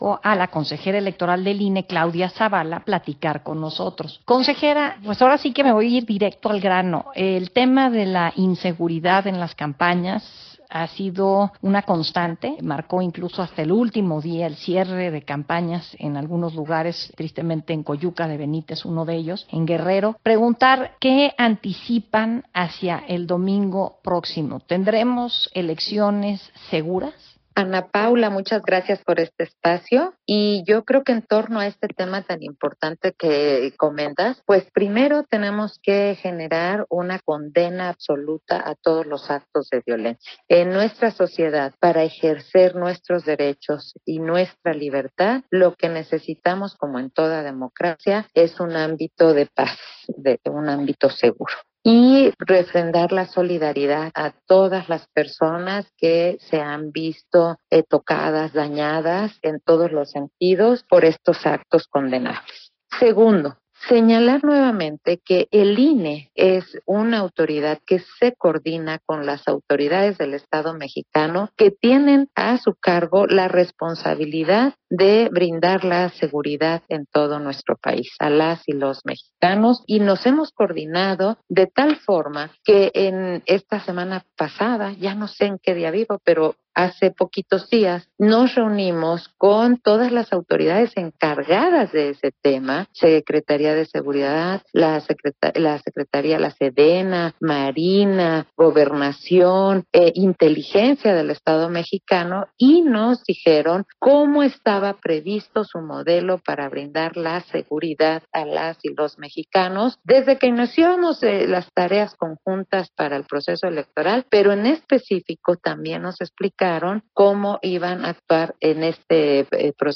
Audio de la entrevista de Claudia Zavala con Ana Paula Ordorica